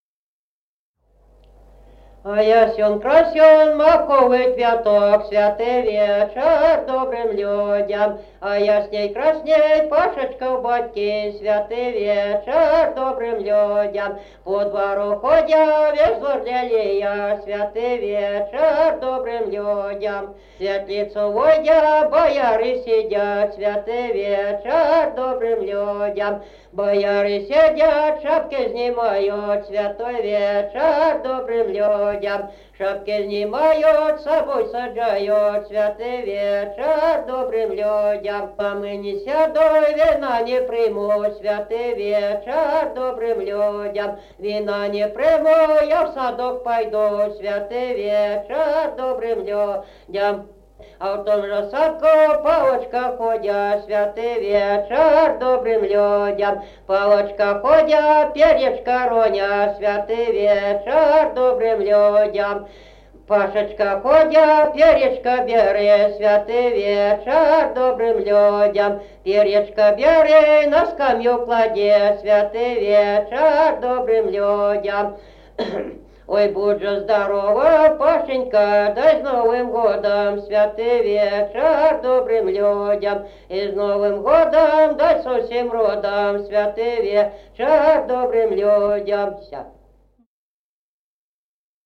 Народные песни Стародубского района «А ясён, красён», новогодняя щедровная.
1953 г., д. Камень.